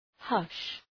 Προφορά
{hʌʃ}